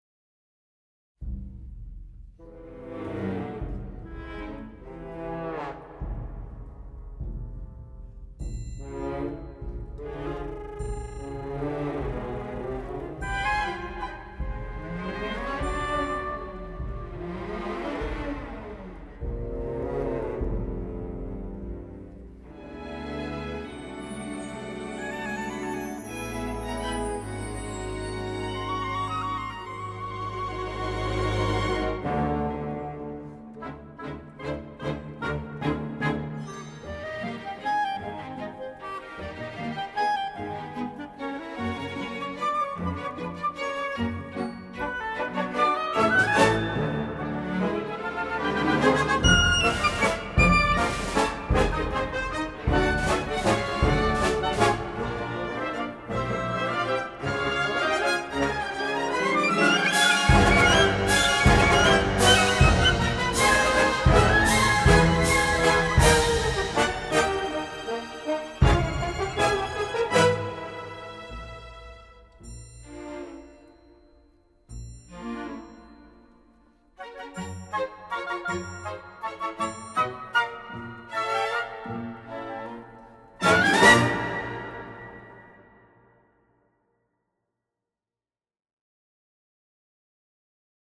A suite of colourful orchestral pieces from the movie score.
Instrumentation:Double-wind orchestra